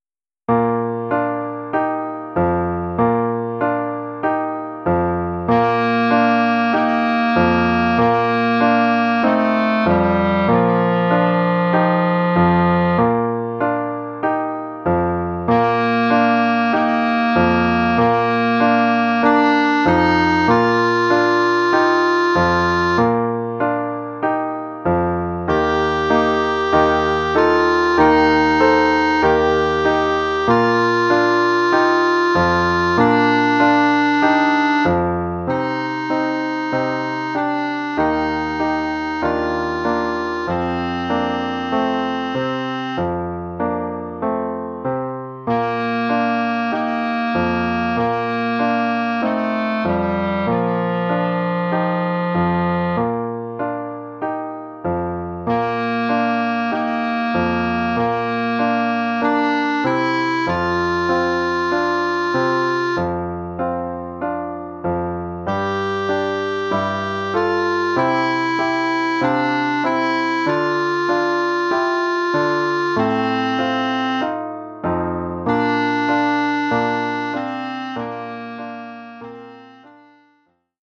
Oeuvre pour clarinette et piano.
Niveau : débutant.